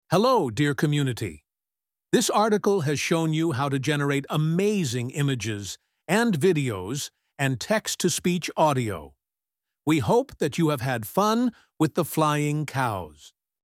🗣 Text-to-Speech: Convert Any Text into High-Quality Audio
Model: Eleven Labs Multilingual V2, Voice: “Bill”
straico-tts-example-api.mp3